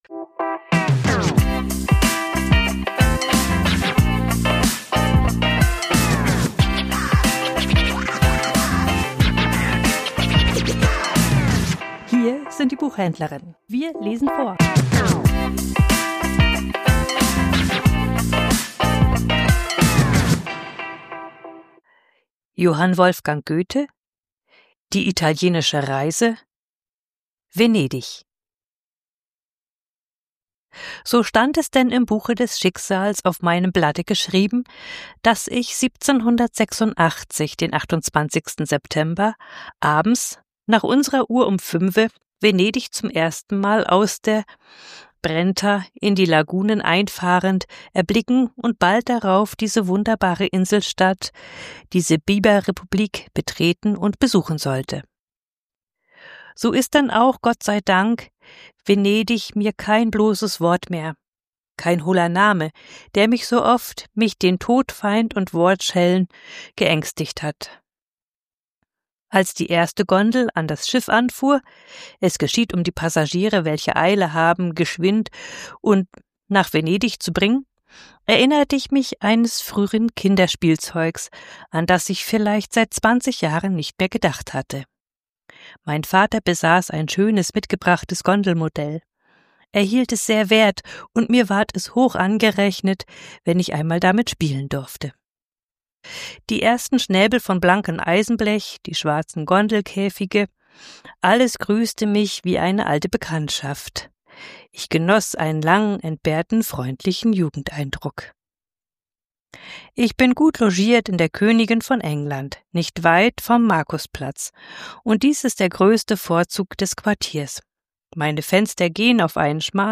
Vorgelesen: Venedig ~ Die Buchhändlerinnen Podcast